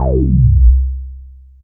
15Bass16.WAV